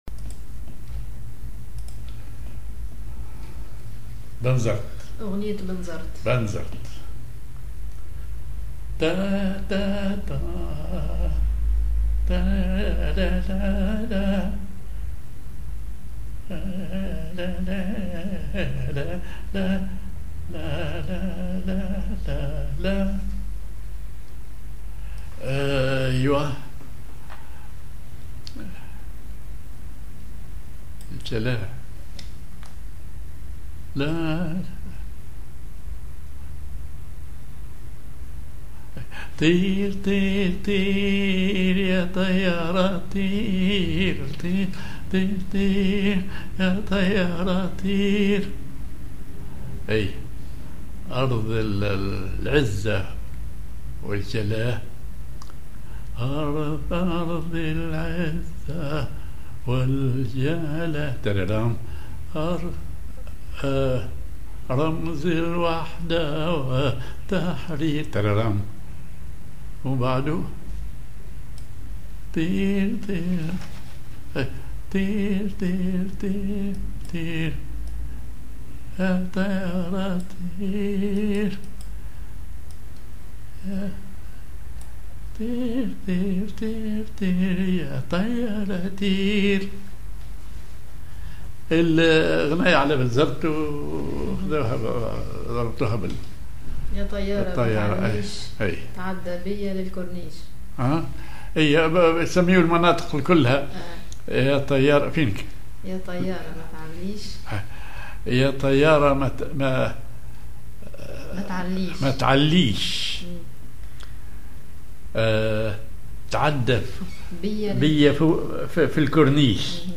Maqam ar راست
genre أغنية